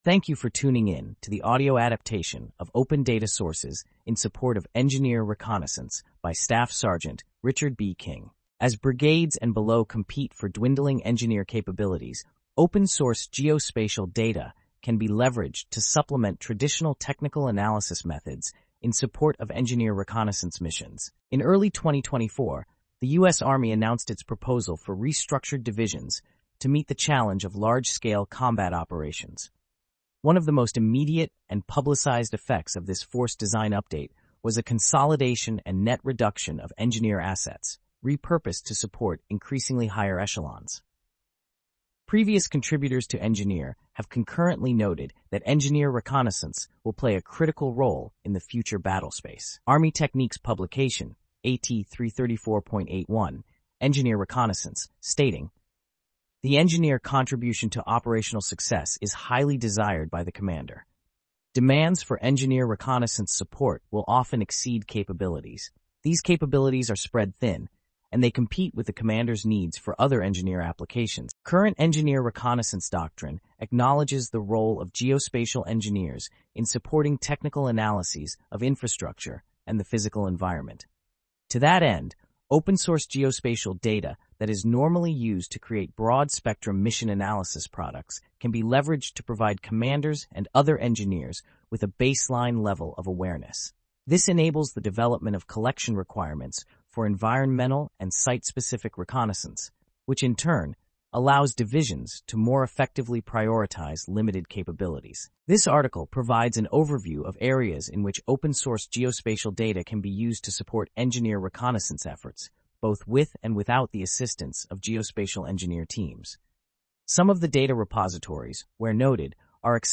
Here, you'll find a collection of audio recordings of the written articles, enhancing accessibility and convenience for our readers.